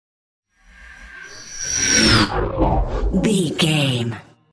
Horror whoosh electronic
Sound Effects
In-crescendo
Atonal
tension
ominous
eerie